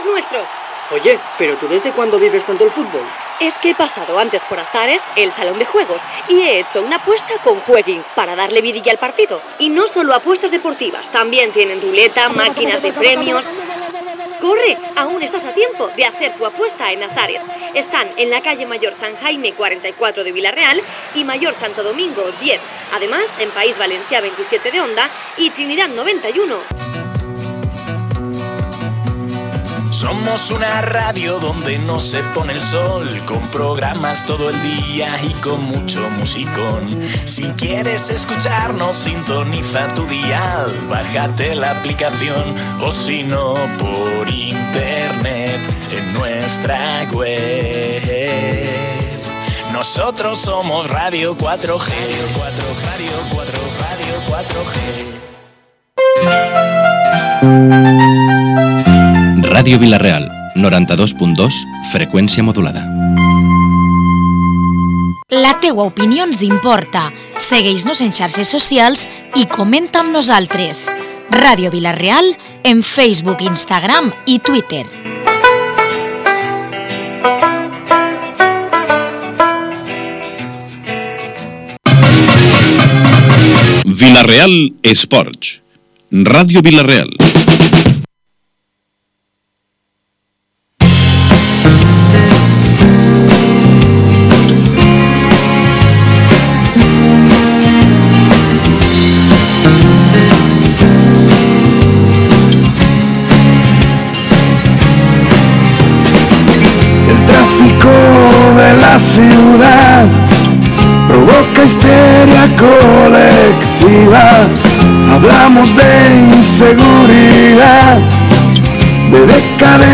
La tertúlia d’Esports de Radio Vila-real, 8 de abril 2019.
La tertúlia de los lunes en Ràdio Vila-real.